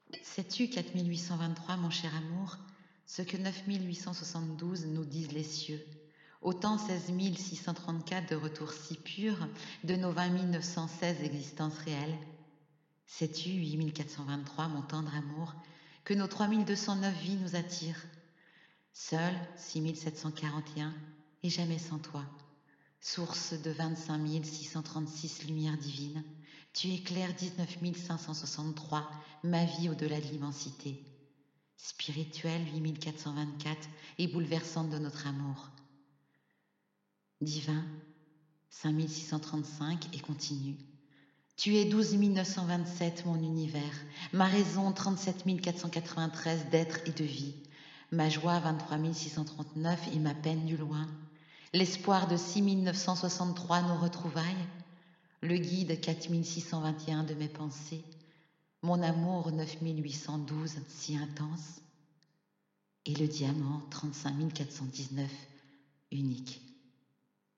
Voix off Biennale de Paris 2020